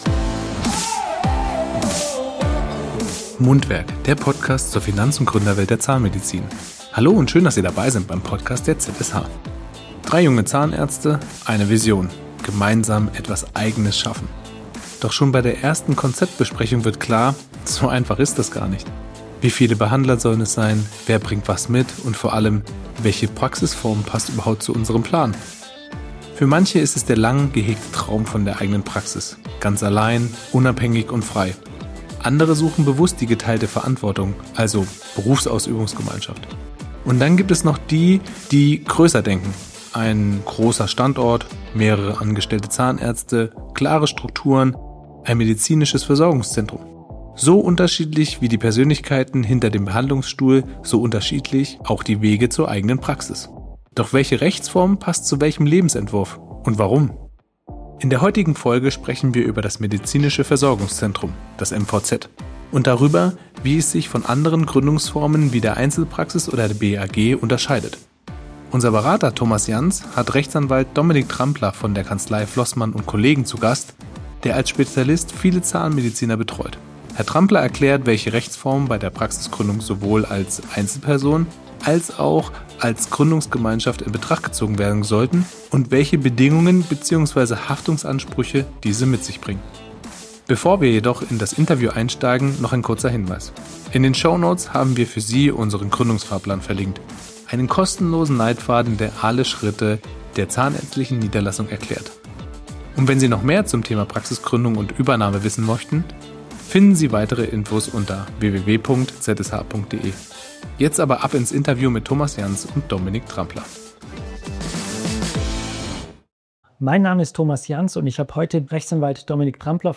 Vom Anwalt erklärt: Was bei der Gründung eines zahnmedizinischen Versorgungszentrums zu beachten ist